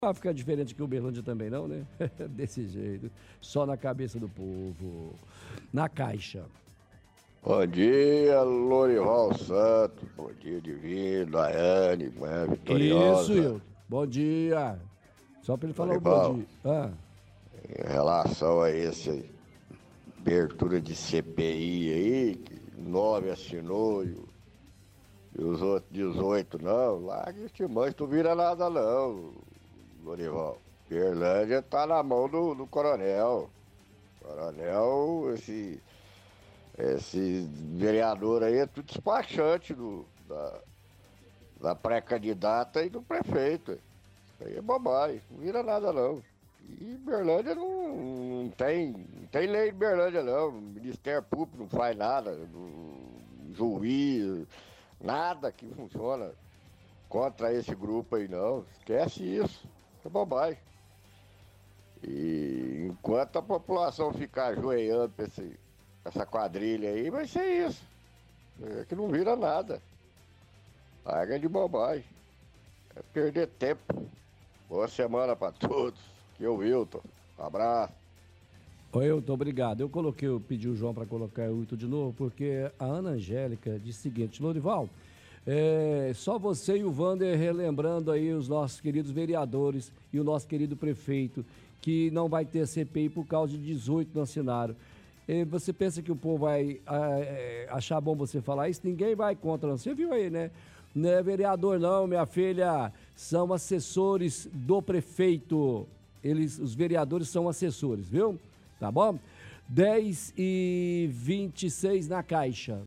– Ouvinte comenta sobre abertura da CPI, diz que Uberlândia está nas mãos do coronel, vereadores são todos despachantes da pré-candidata e do prefeito.